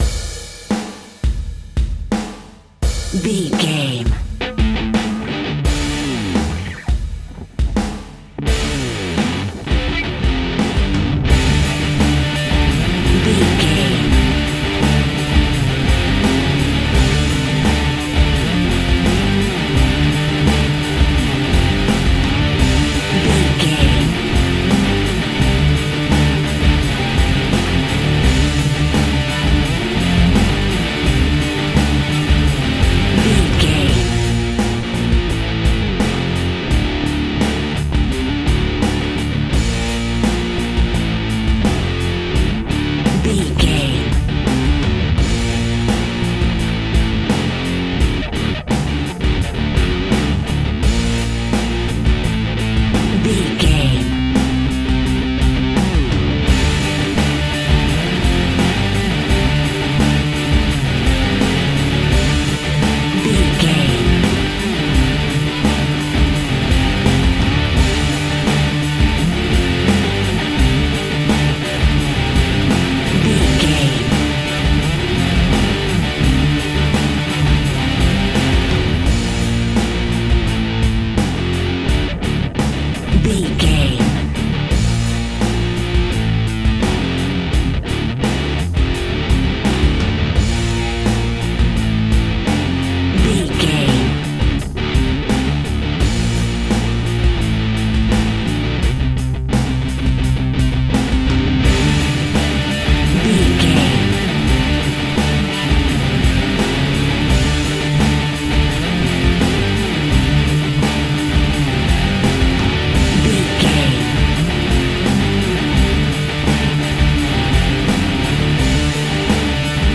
Rocking Power Metal.
Ionian/Major
energetic
driving
heavy
aggressive
electric guitar
bass guitar
drums
heavy metal
distortion
Instrumental rock